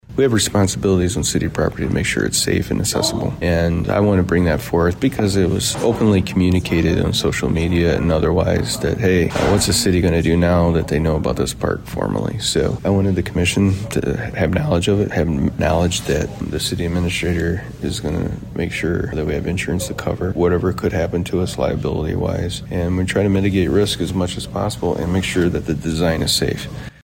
Adrian City Administrator Chad Baugh.